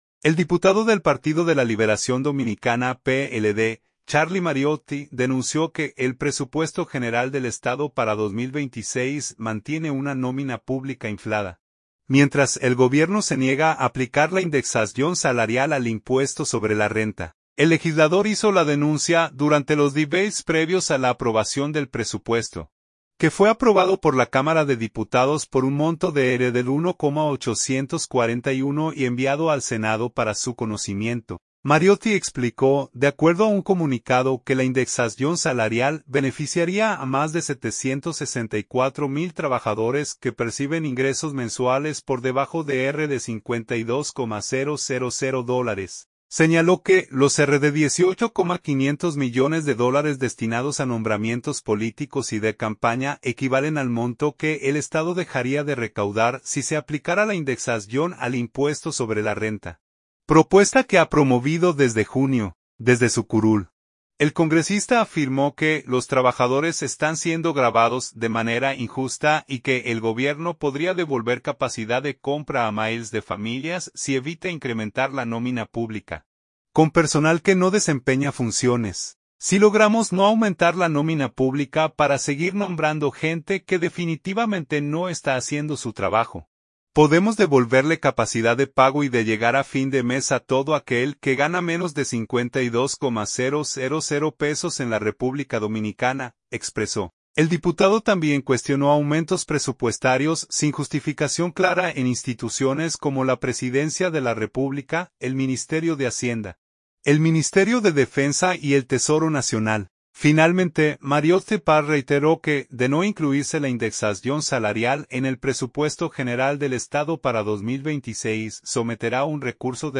El legislador hizo la denuncia durante los debates previos a la aprobación del presupuesto, que fue aprobado por la Cámara de Diputados por un monto de RD$1,841,701,394,621 y enviado al Senado para su conocimiento.
• Desde su curul, el congresista afirmó que los trabajadores están siendo gravados de manera injusta y que el Gobierno podría devolver capacidad de compra a miles de familias si evita incrementar la nómina pública con personal que no desempeña funciones.